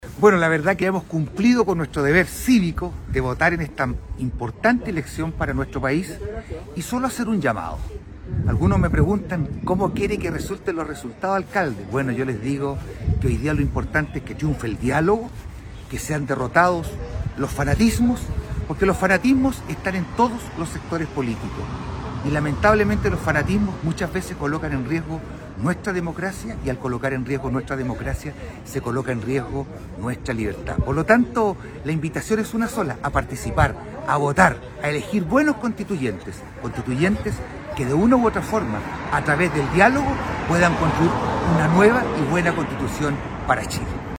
Así lo declaró el alcalde Juan Eduardo Vera al emitir su sufragio esta mañana en la mesa 73 de la escuela Luis Uribe Díaz y precisó además que esta elección es una oportunidad única para hacer cambios significativos en la estructura política, social, económica y cultural del país.